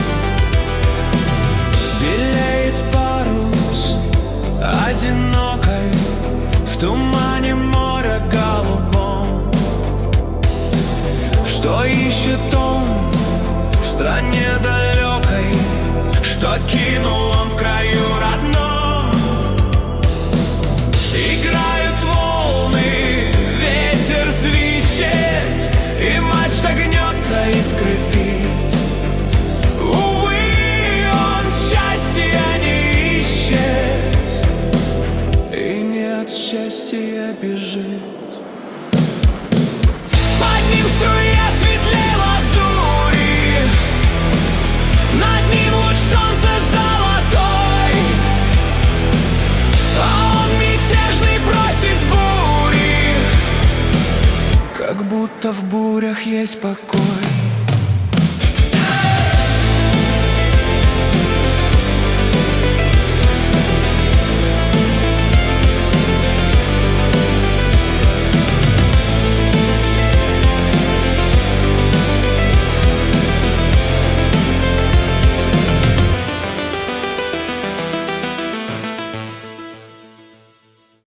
Качество: 239 kbps, stereo
Поп музыка